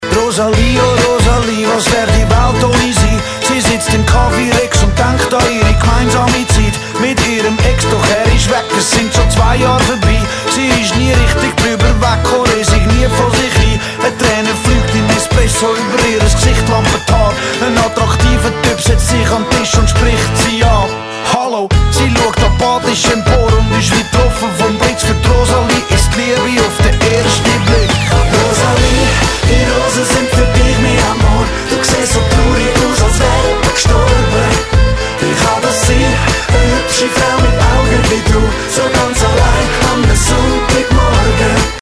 Swiss German rap